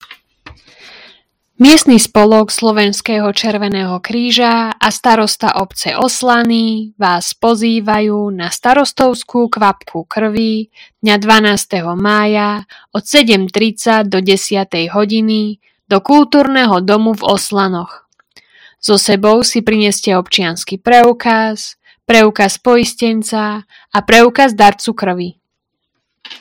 Hlásenie obecného rozhlasu – Darovanie krvi 12.05.2025 v KD Oslany